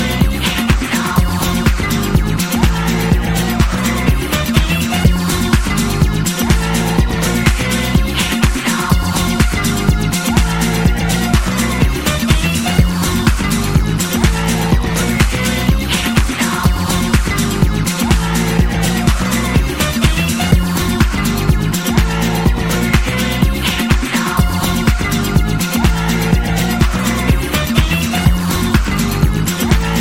Styl: Disco, House, Breaks/Breakbeat